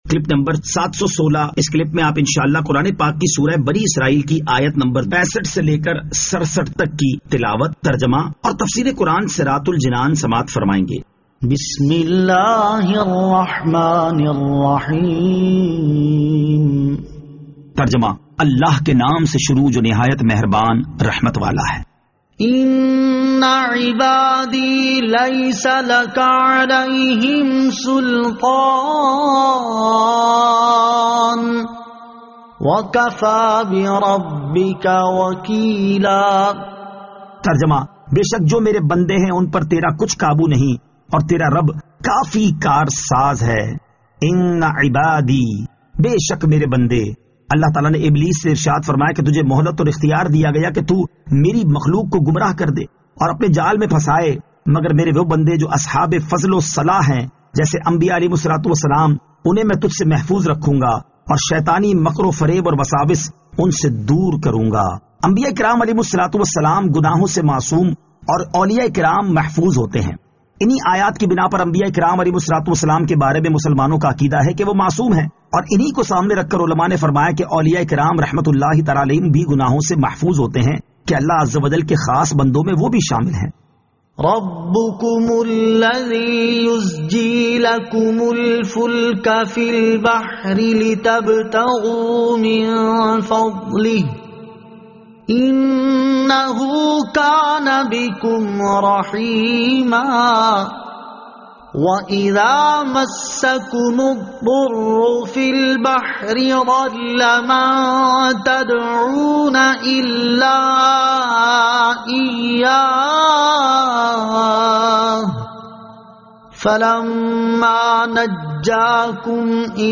Surah Al-Isra Ayat 65 To 67 Tilawat , Tarjama , Tafseer